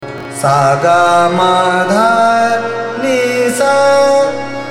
ThaatKhammaj
ArohaS G m D n S’